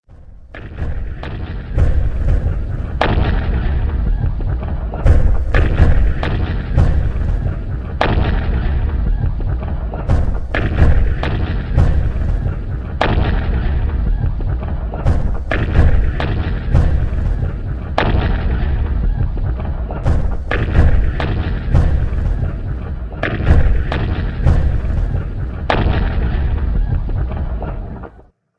BombExplosionRingtone.mp3